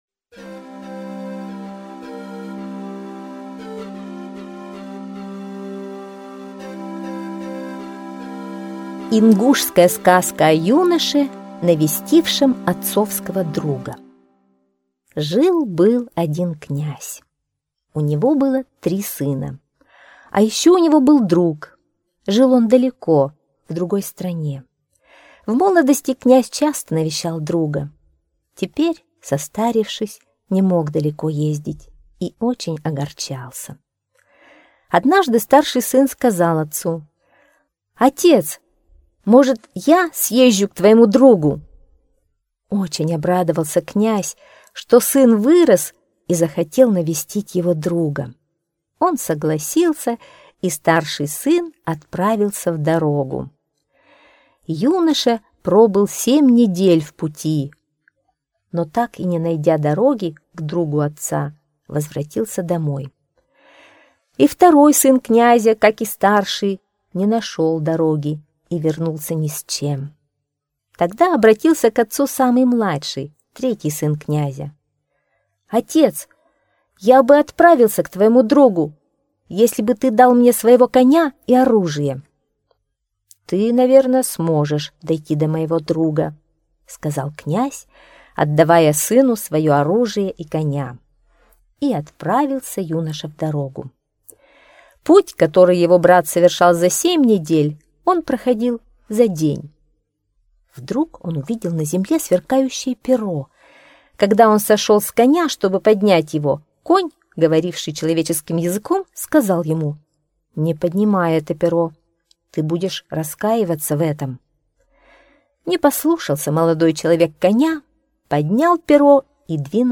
Сказка о юноше, навестившем отцовского друга - ингушская аудиосказка.